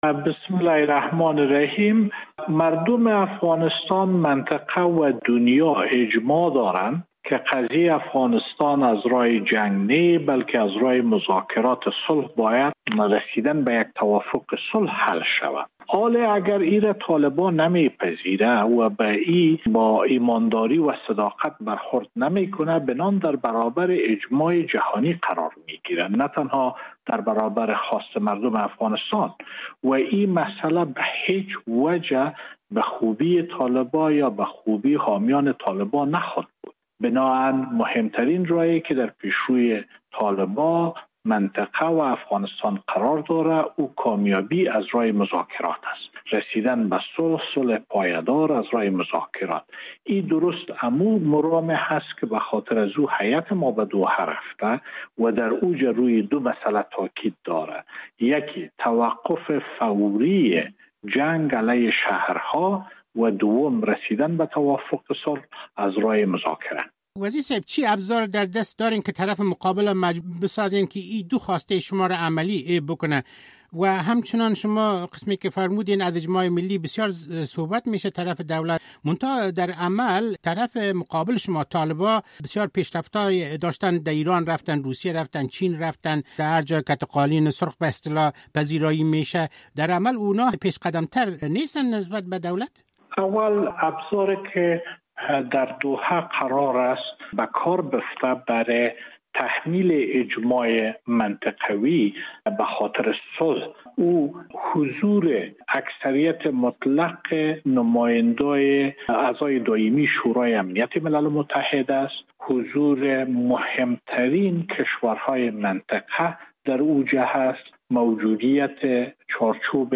مصاحبه - صدا
آقای اتمر این را امروز پنجشنبه (۲۱ اسد) در گفت‌وگوی اختصاصی به رادیو آزادی گفت.